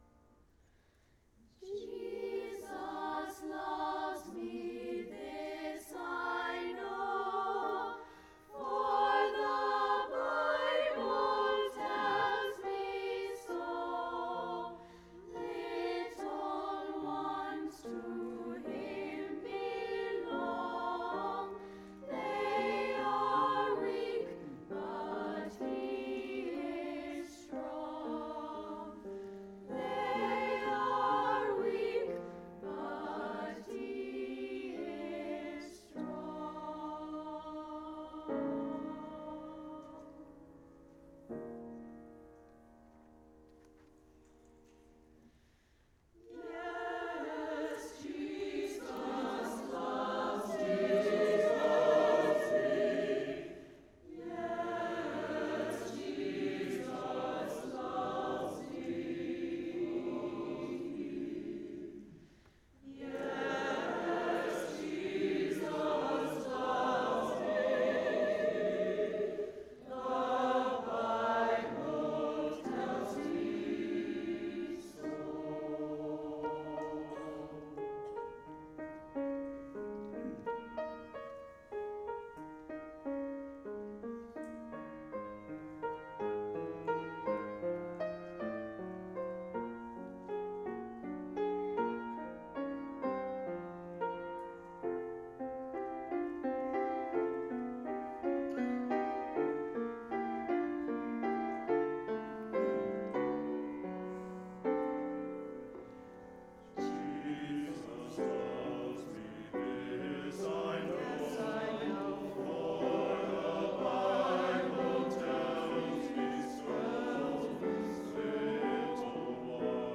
March 2, 2014 Concert
The St. Louis Children’s Choir
St. Louis Choir
Jesus Loves Me, arranged by Jay Dausch (with the children)